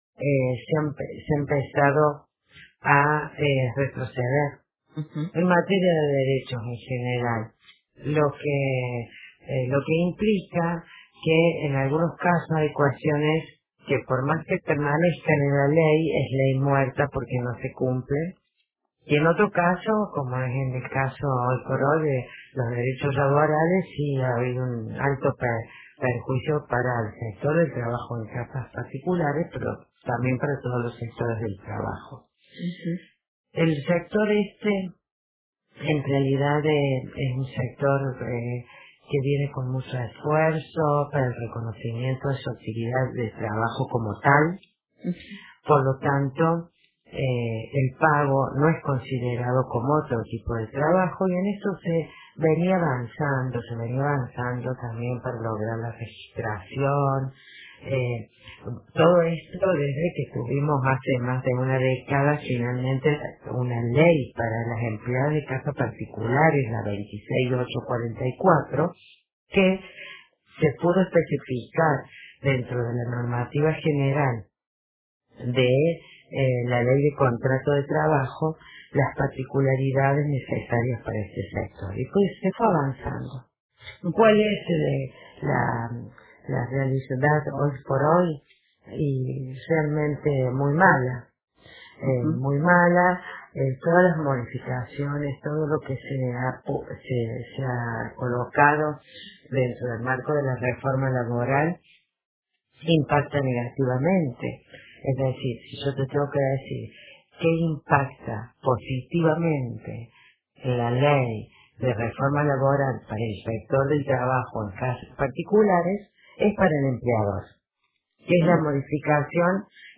En diálogo con Radio Universidad